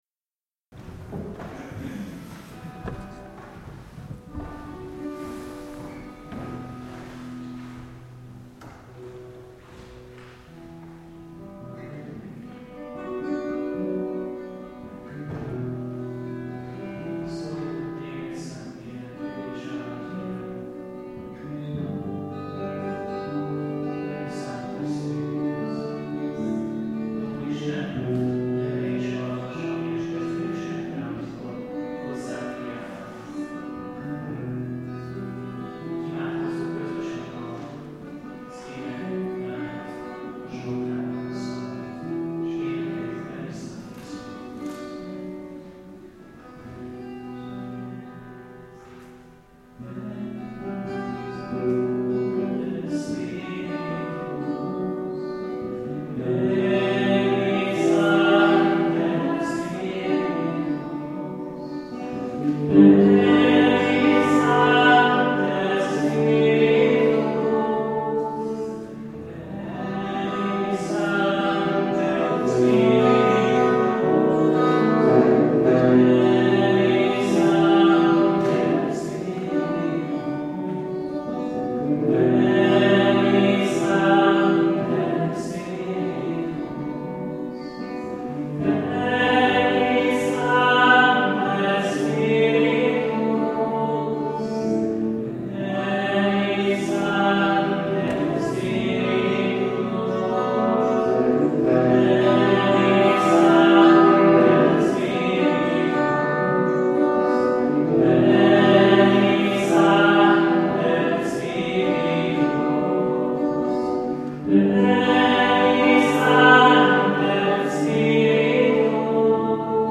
mp3 (amatőr felvétel)